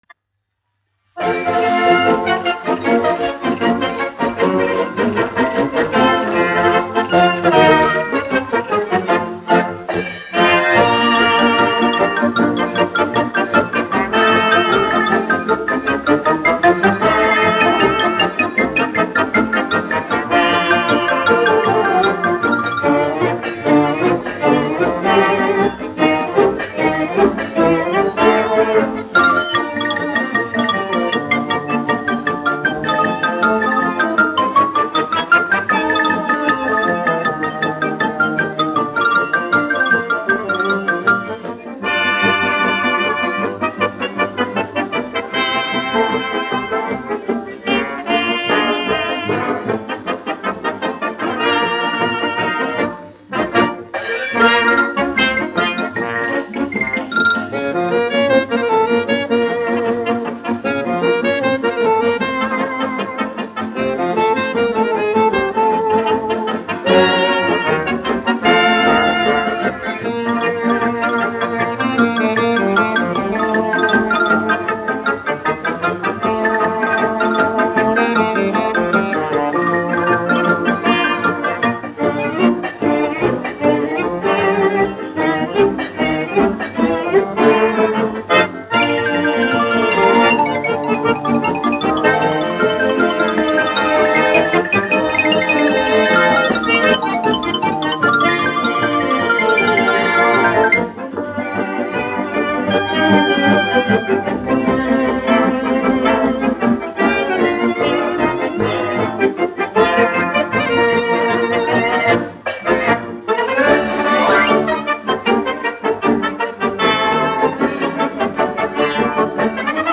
1 skpl. : analogs, 78 apgr/min, mono ; 25 cm
Populārā instrumentālā mūzika
Sarīkojumu dejas
Skaņuplate